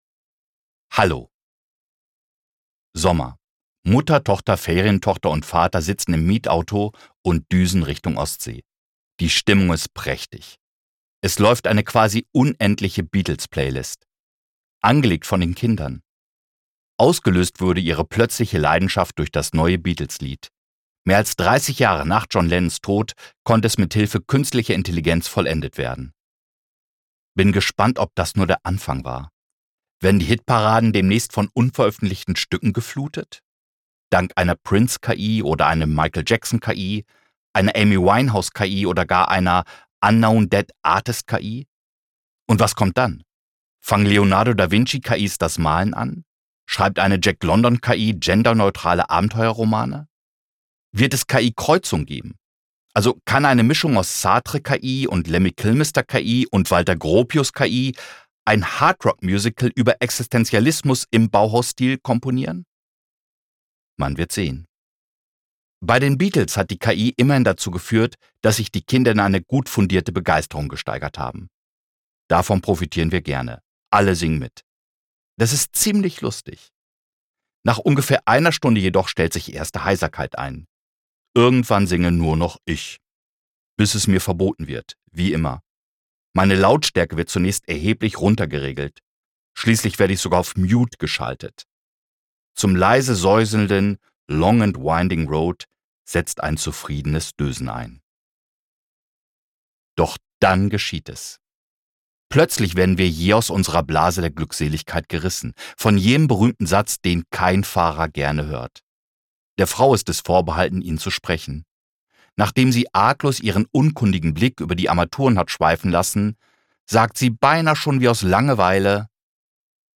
Rezension: Horst Evers spricht sein Buch – Zu faul zum Nichtstun
Interpret: Horst Evers